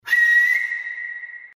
siul Meme Sound Effect
This sound is perfect for adding humor, surprise, or dramatic timing to your content.
Category: TikTok Soundboard